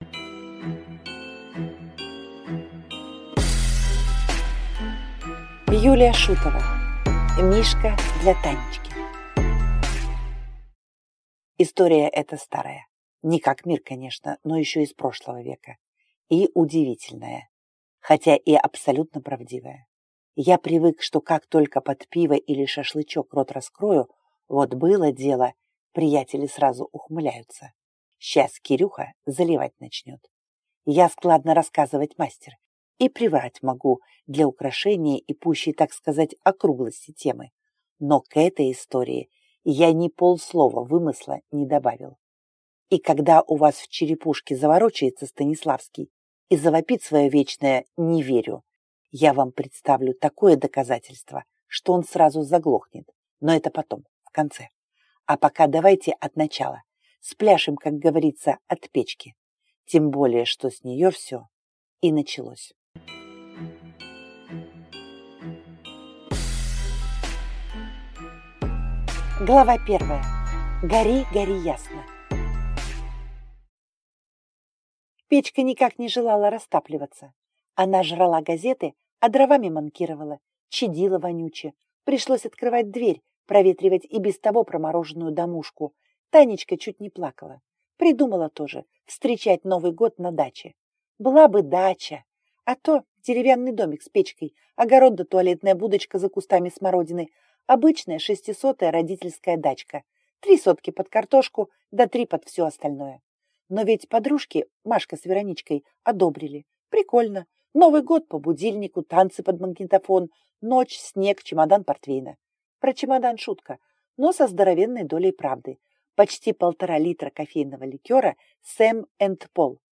Аудиокнига Мишка для Танечки | Библиотека аудиокниг